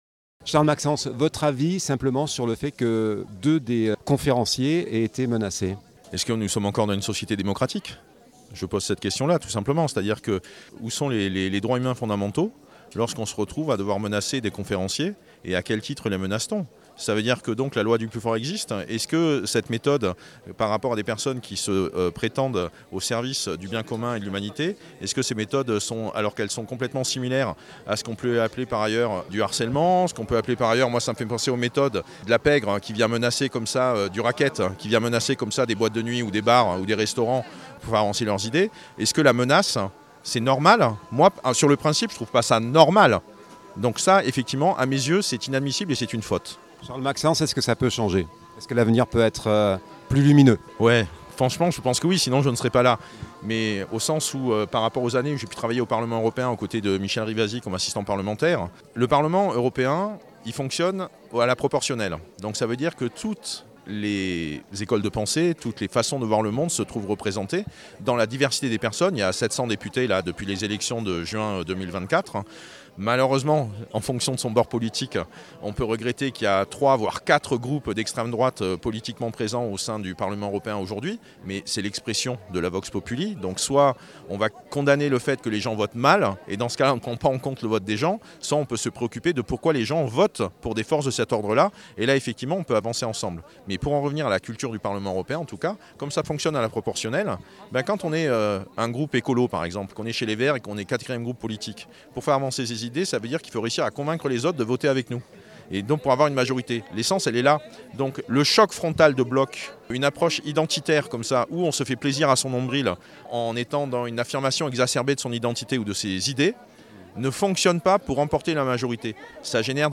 Manifestation-Réactions.mp3 (7.59 Mo)